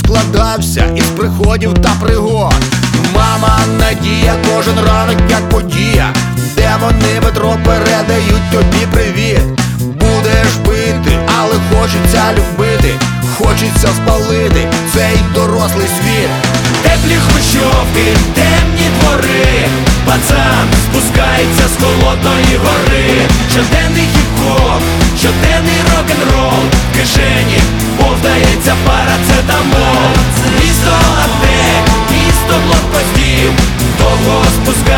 Жанр: Хип-Хоп / Рэп / Рок / Украинские